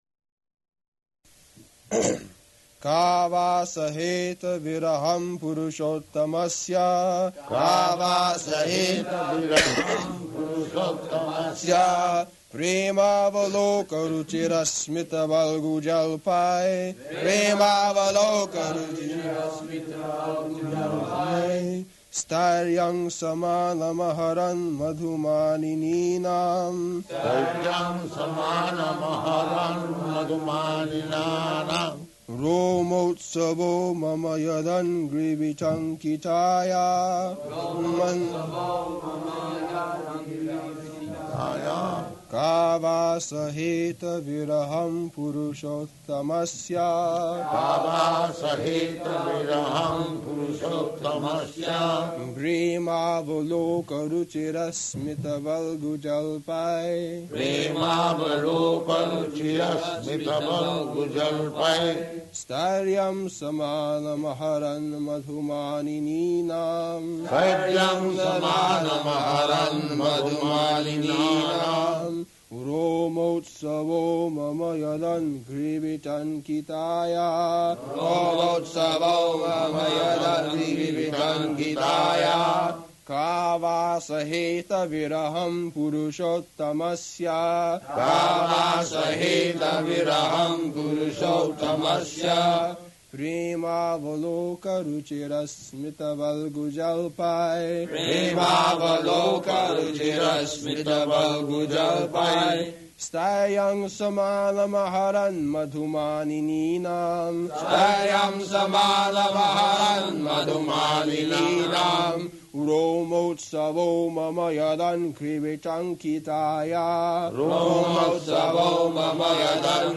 Location: Honolulu
[Prabhupāda and devotees repeat]